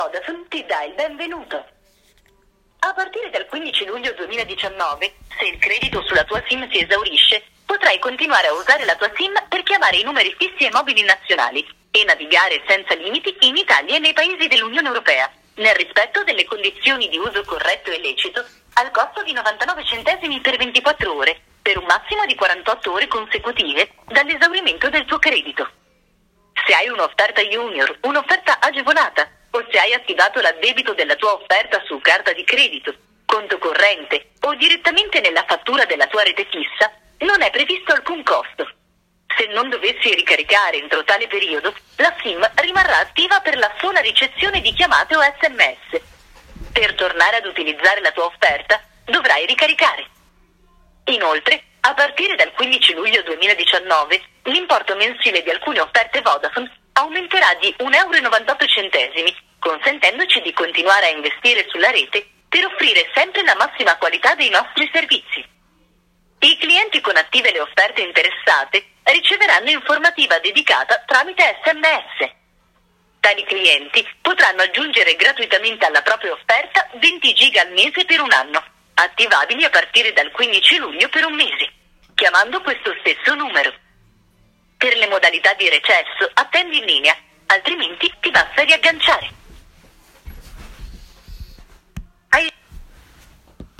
Come indicato nell’SMS e poi esplicitato meglio dal messaggio audio disponibile chiamando il numero 42590, a partire dal 15 Luglio 2019 in caso di credito negativo si continuerà sempre a chiamare e a navigare senza limiti, ma il costo sarà di 99 centesimi di euro per 24 ore, fino ad un massimo di 48 ore.